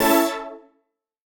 Index of /musicradar/future-rave-samples/Poly Chord Hits/Straight
FR_PHET[hit]-A.wav